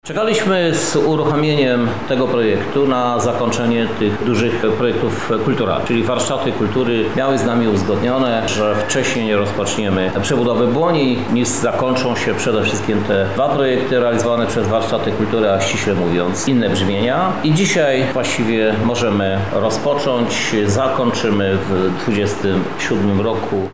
Krzysztof Żuk– mówi Krzysztof Żuk, Prezydent Miasta Lublin